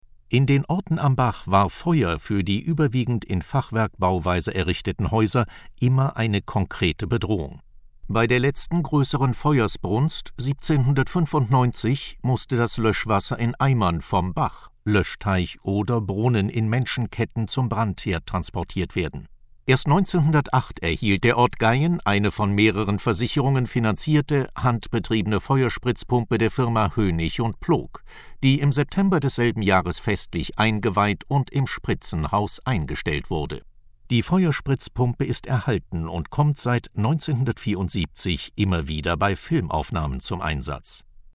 Feuerspritzpumpe von 1908; Aufnahme vom 22. August 2009